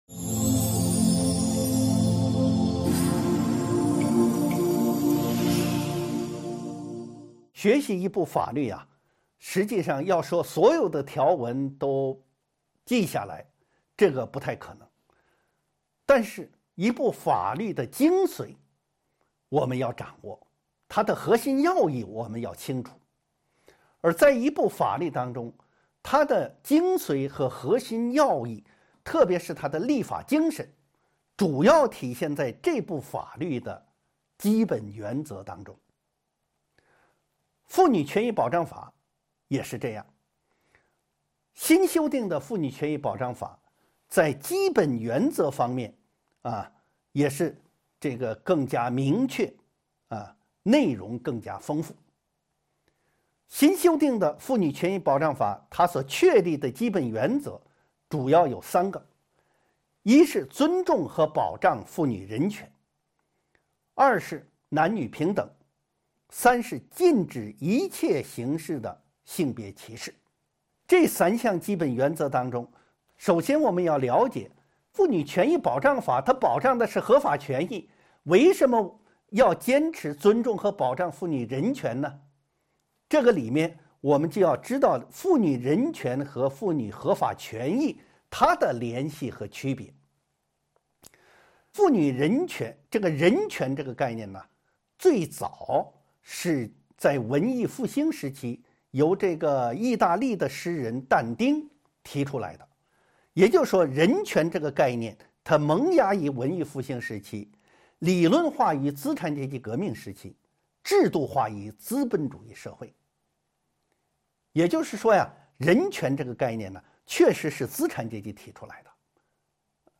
音频微课:《中华人民共和国妇女权益保障法》7.妇女权益保障法的基本原则及其作用